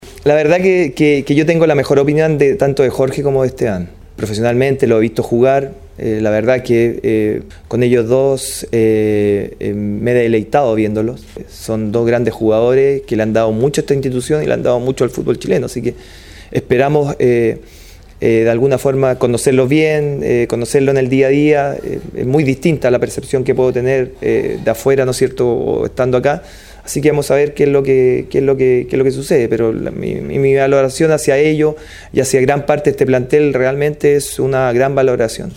No pudo ocultar su emoción. Mario Salas, el flamante nuevo director técnico de Colo Colo, protagonizó una conferencia llena de emotividad en su presentación oficial.